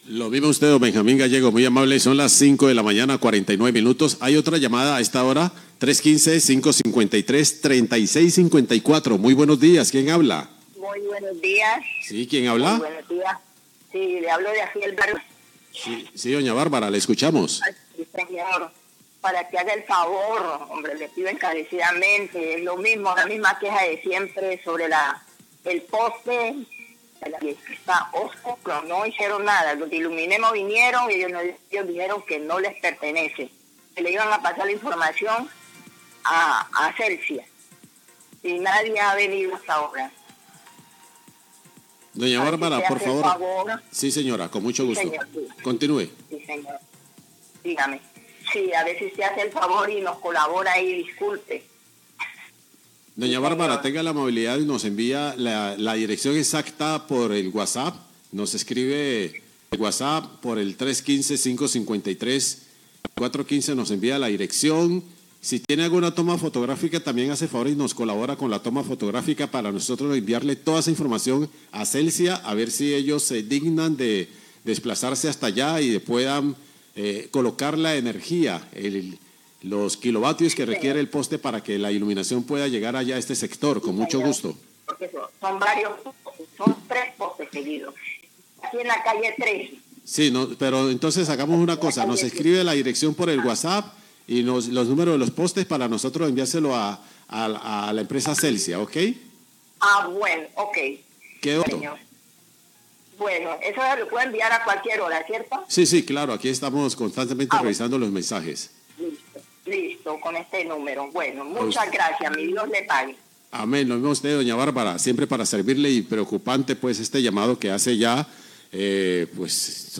Oyente reporta lámpara de alumbrado publico sin energía ,554am
Radio